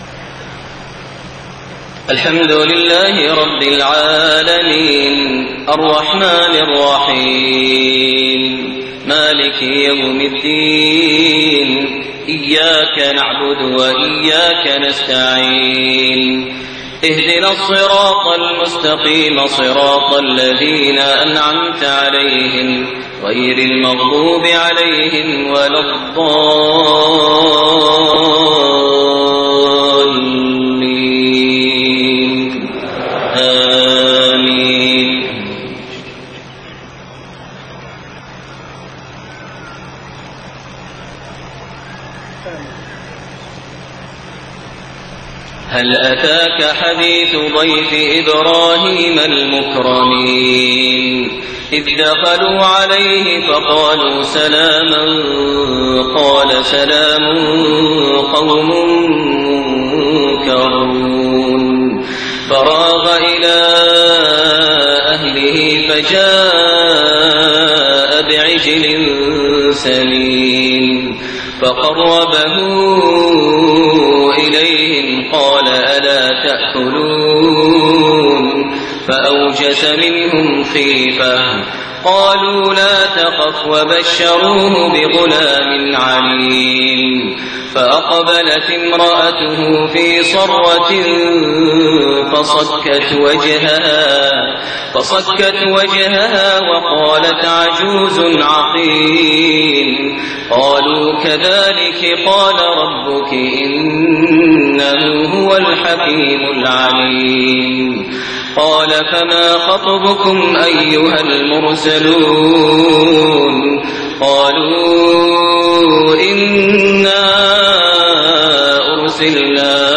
Isha prayer from Surat Adh-Dhaariyat > 1429 H > Prayers - Maher Almuaiqly Recitations